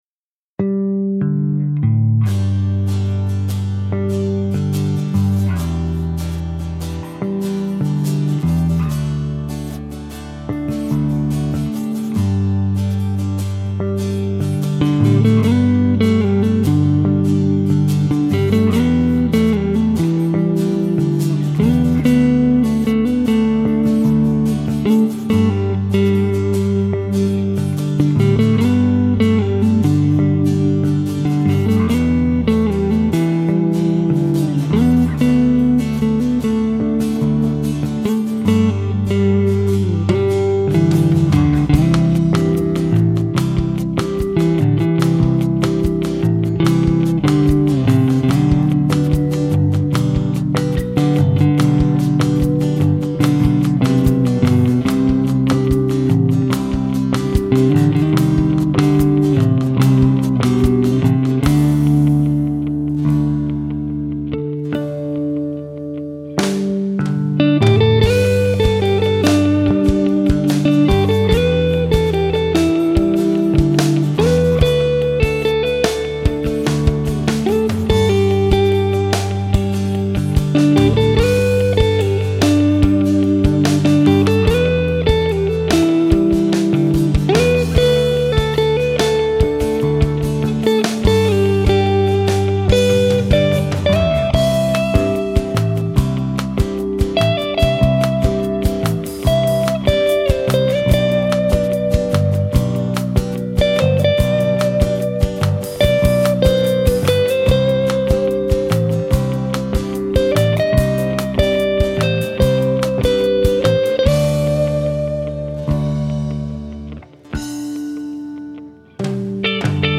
Гитара поёт!
По характеру же звук ближе к хамбакеру, что и привело меня в замешательство при первом знакомстве со звуком Godin Triumph.
Godin Triumph Sparkle BlueГитара обладает прекрасным сустейном.
1. Godin Triumph Sparkle Blue demo song 5,46 Мб